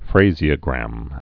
(frāzē-ə-grăm)